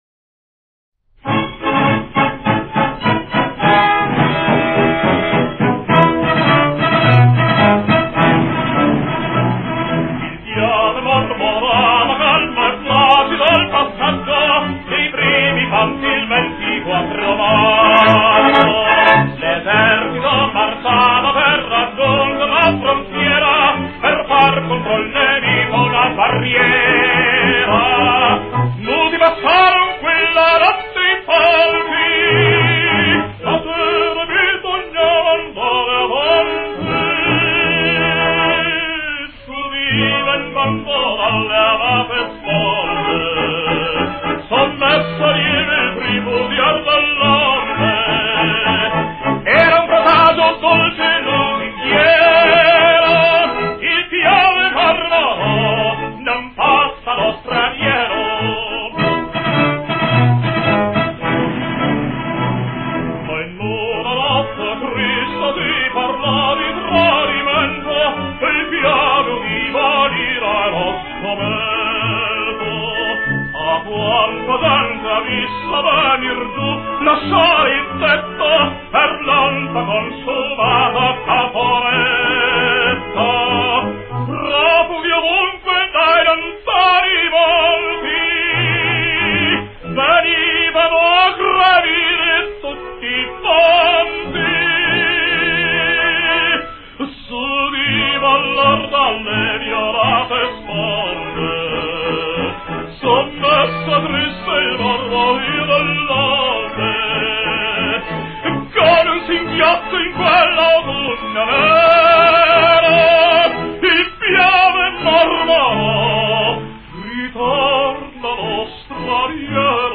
La Canzone del Piave, altrimenti nota come La Leggenda del Piave è una delle più celebri canzoni patriottiche italiane.
Con il suo tono gagliardo ed entusiasta, a cui si accompagnavano nella gioia generale i progressi sul fronte dell'esercito italiano, essa contribuì a risollevare la speranza degli Italiani in un esito positivo del conflitto.